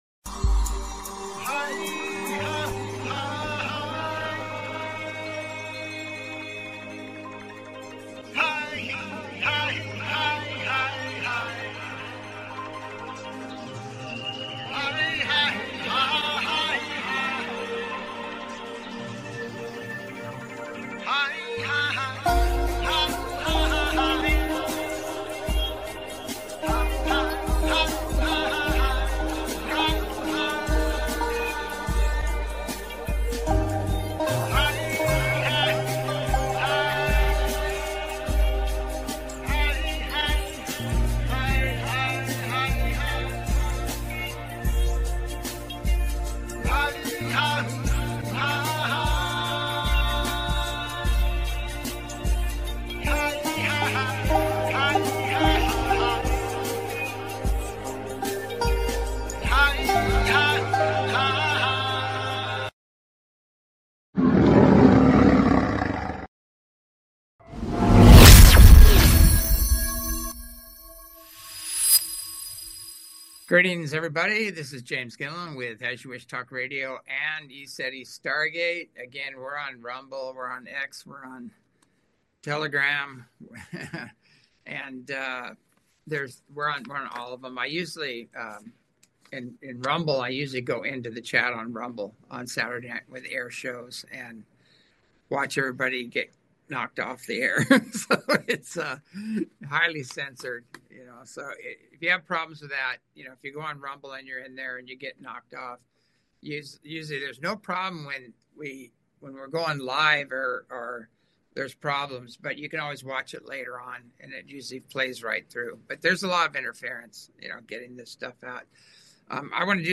Talk Show Episode, Audio Podcast, As You Wish Talk Radio and UFOs/UAPs, Time Folds, End of Tyranny on , show guests , about UFOs/UAPs,Time Folds,End Of Tyranny, categorized as Earth & Space,News,Paranormal,UFOs,Philosophy,Politics & Government,Science,Spiritual,Theory & Conspiracy